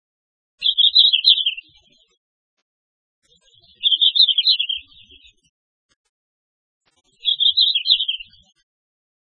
2126e「鳥の鳴声」
〔ルリビタキ〕ヒッヒッ／クルル／キョロキョロキョロリ（さえずり）／高山の針葉樹
ruribitaki.mp3